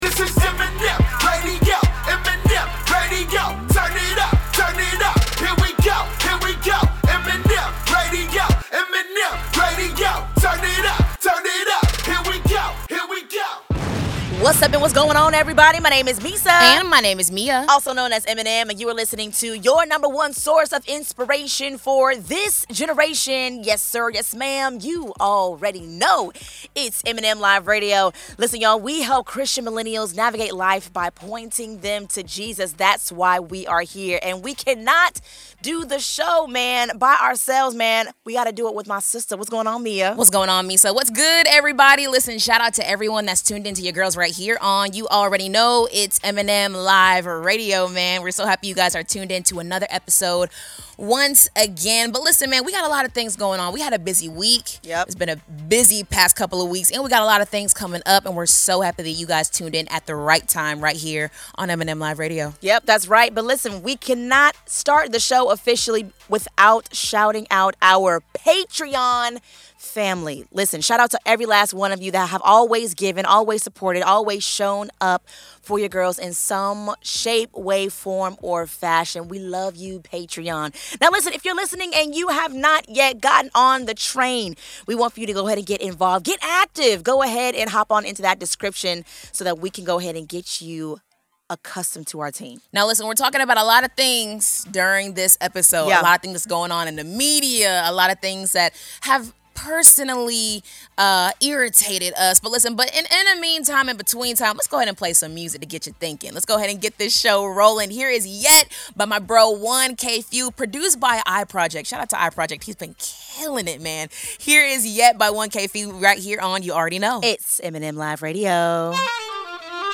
Through inspiring music, powerful stories, and thought-provoking interviews, this is the show where faith meets culture—all to point you back to Jesus.